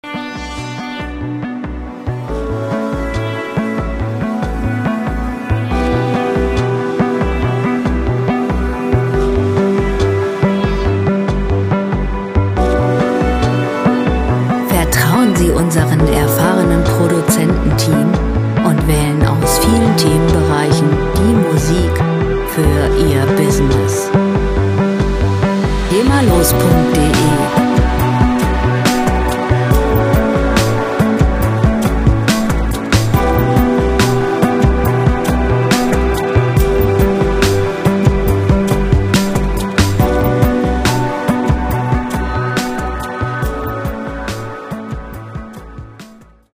Lounge Musik - Ruhe und Entspannung
Musikstil: Spa Music
Tempo: 70 bpm
Tonart: H-Moll
Charakter: wohltuend, entspannend
Instrumentierung: Synthie, E-Gitarre, Chor